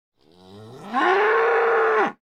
Звуки быка
Грозный бычий рёв